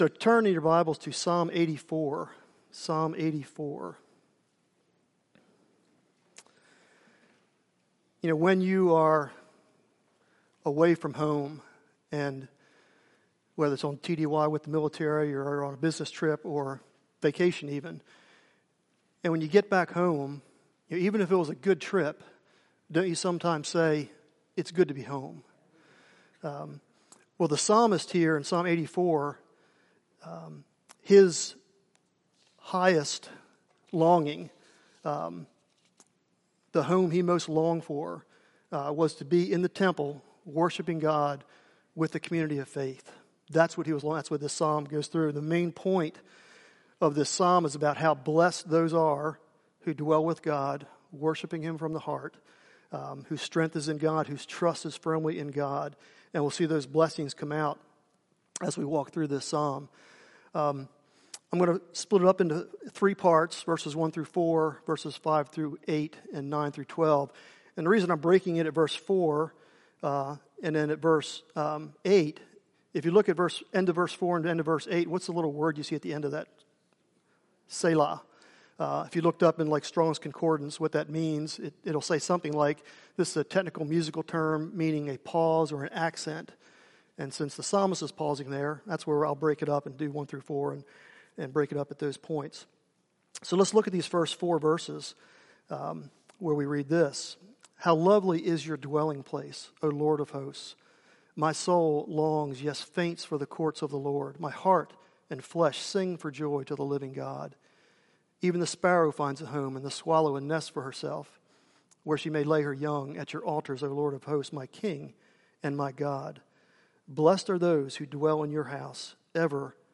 Sunday Evening Preachers Training | Poquoson Baptist Church